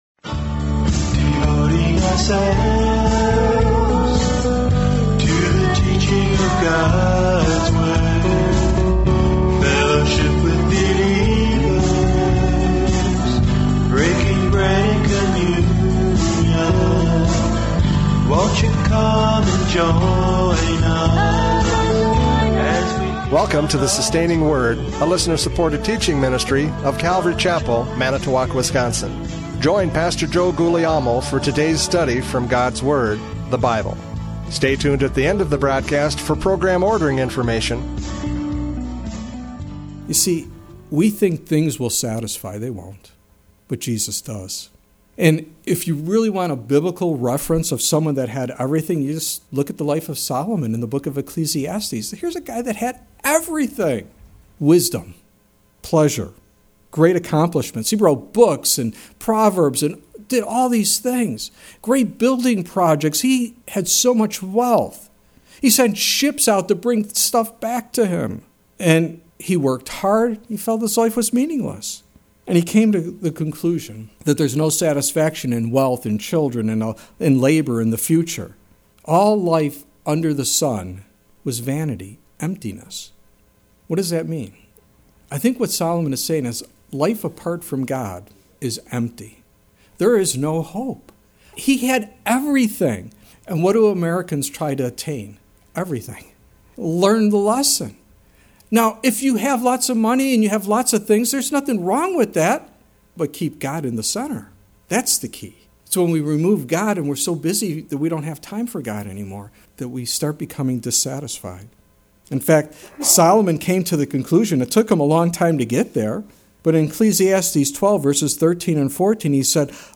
John 4:11-26 Service Type: Radio Programs « John 4:11-26 Living Water!